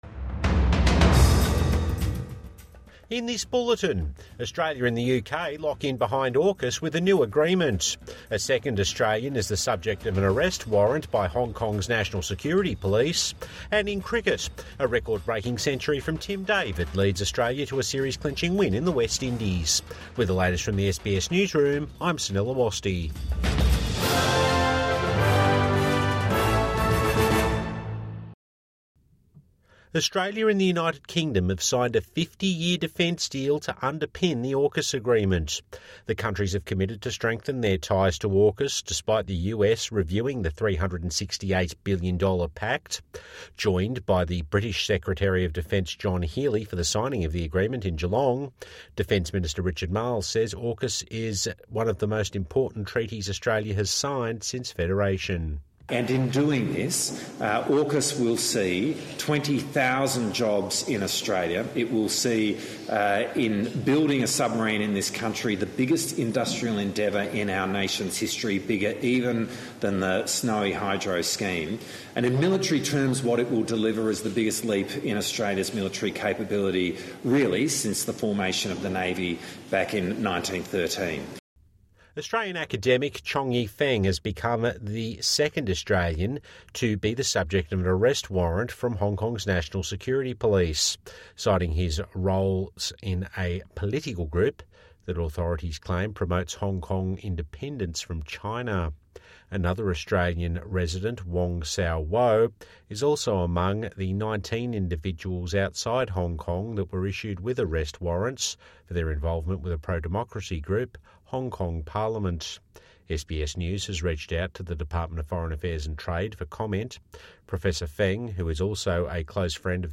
Australia and the UK recommit to each other, and AUKUS | Evening News Bulletin 26 July 2025